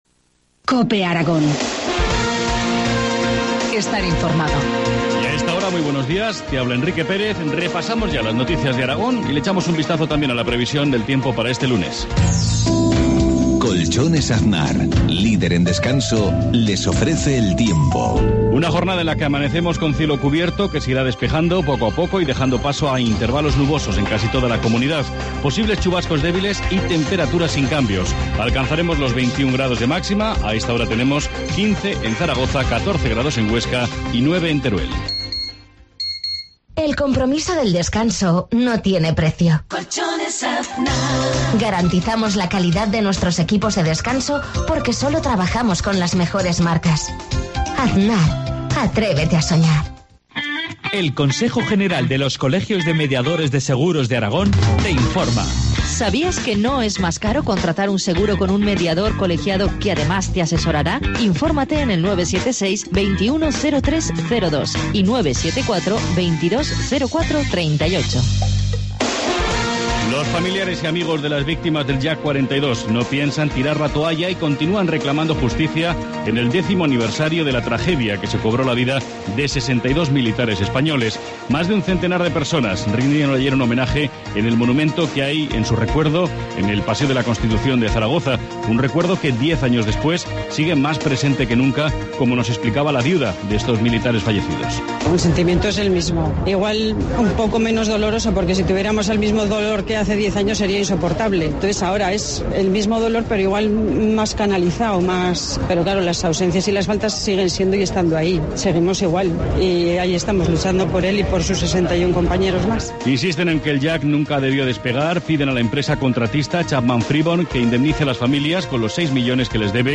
Informativo matinal, lunes 27 mayo 7,52 horas